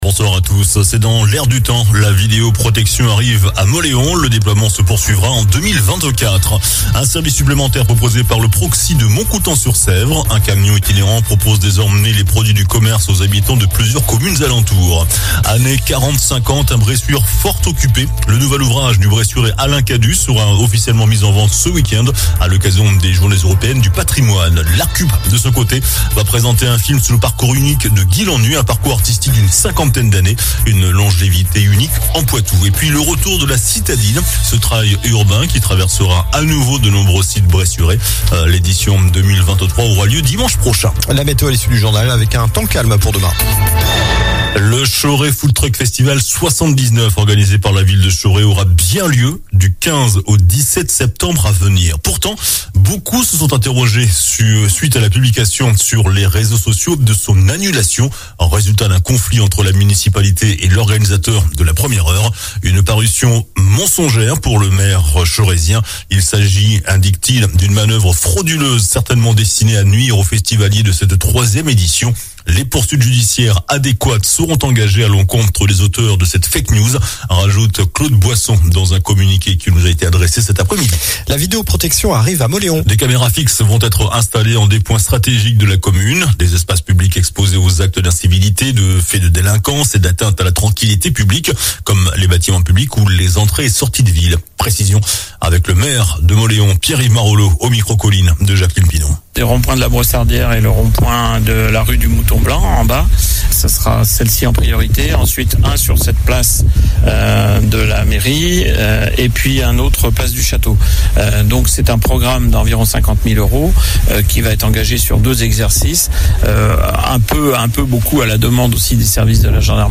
JOURNAL DU MARDI 12 SEPTEMBRE ( SOIR )